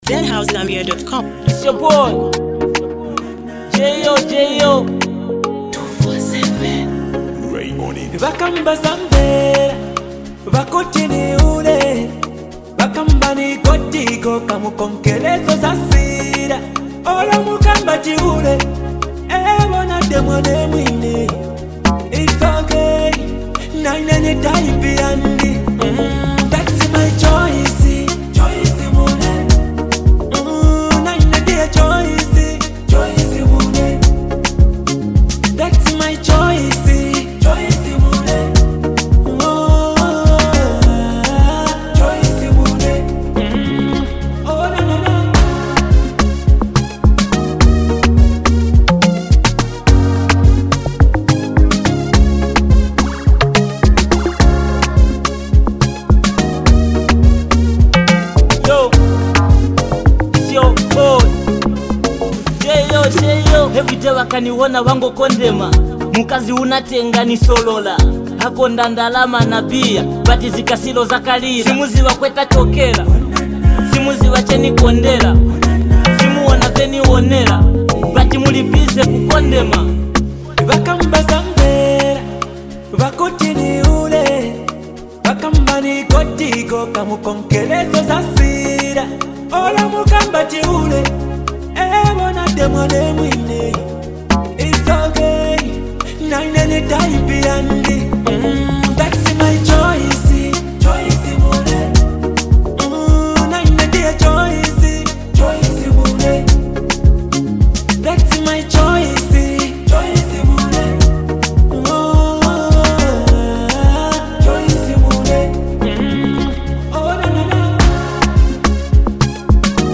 heartfelt love anthem